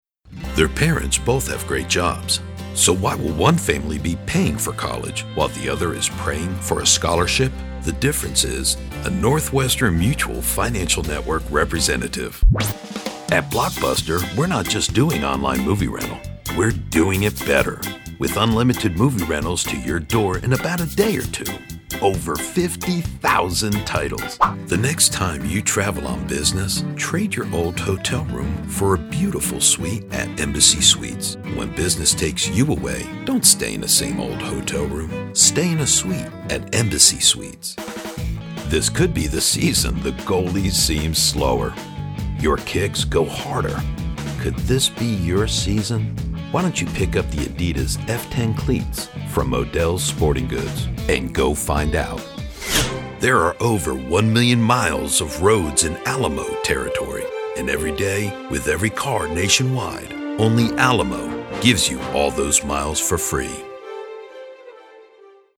Deep english speaking voice, authorative, manly, believable, honest, raspy, rugged.
Sprechprobe: Werbung (Muttersprache):